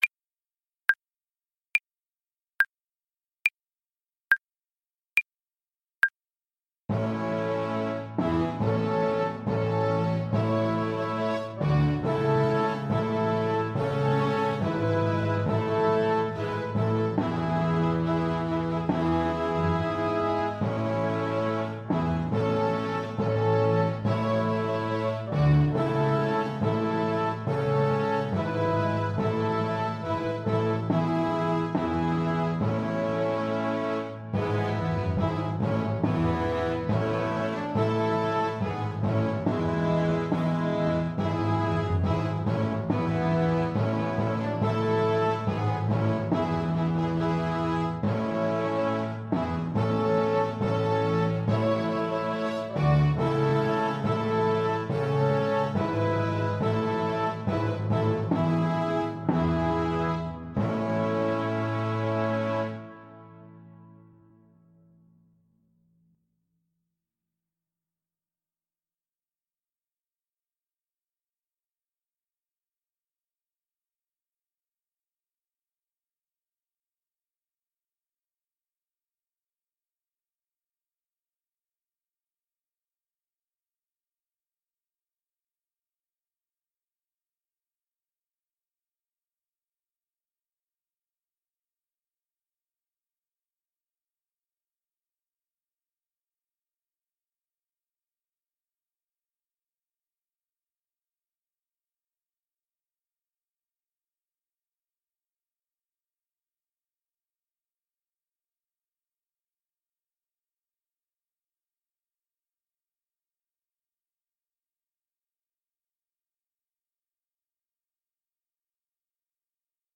Violin version
Allegro Maestoso = 70 (View more music marked Allegro)
2/2 (View more 2/2 Music)
Classical (View more Classical Violin Music)